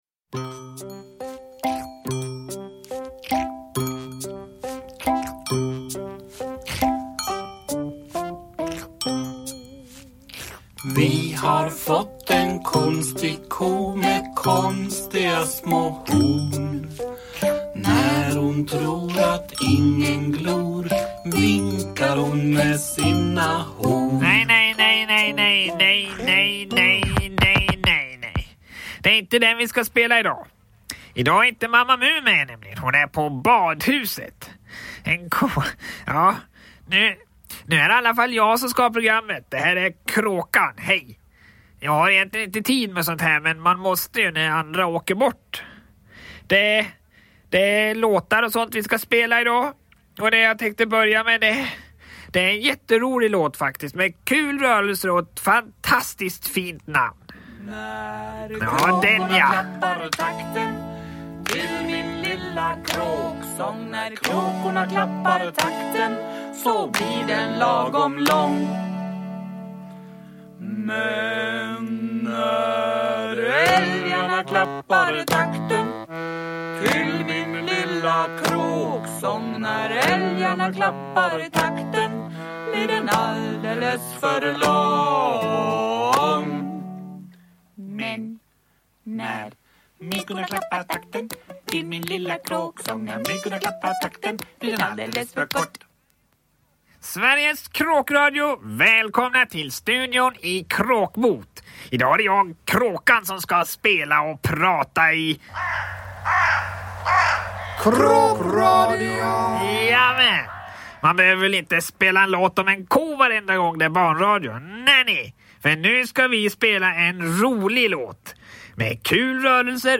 Mamma Mu - Kråkradion – Ljudbok – Laddas ner
Uppläsare: Jujja Wieslander